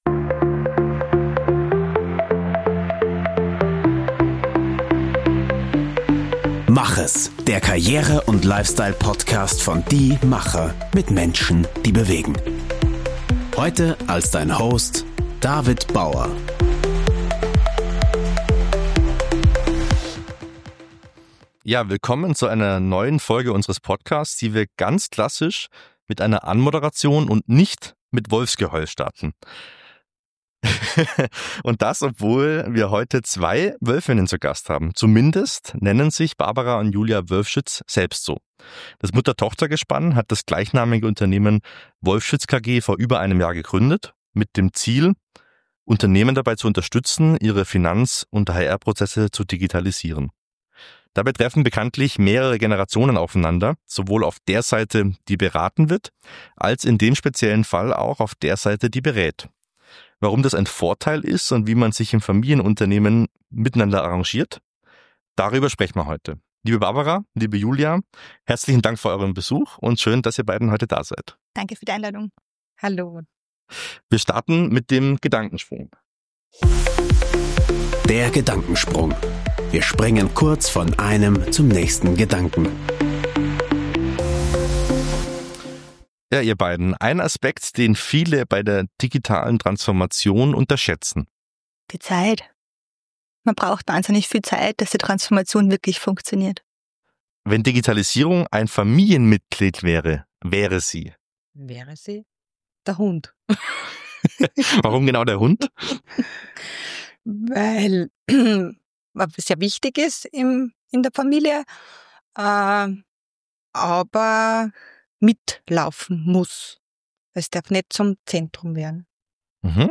Im Interview sprechen Mutter und Tochter über überraschende Gemeinsamkeiten trotz unterschiedlicher Sozialisierung und verraten ihr Erfolgsrezept, damit Digitalisierung nicht zur Generationenfrage wird.